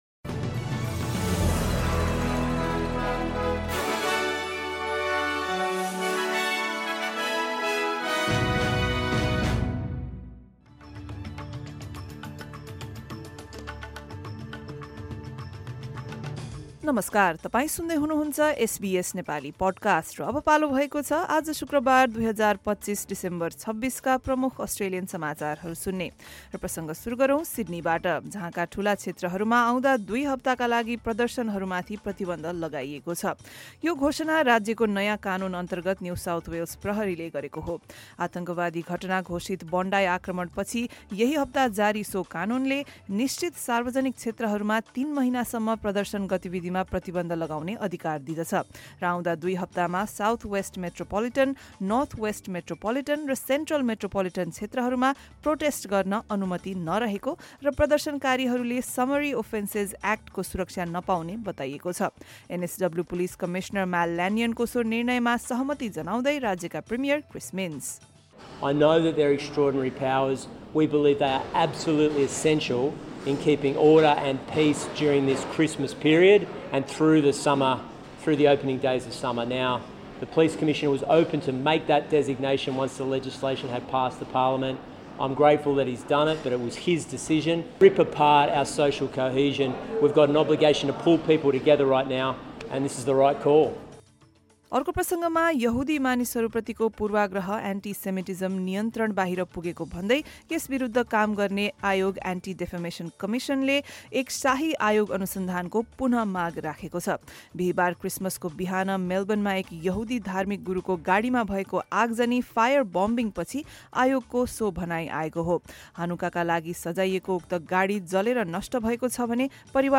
एसबीएस नेपाली प्रमुख अस्ट्रेलियन समाचार: शुक्रवार, २६ डिसेम्बर २०२५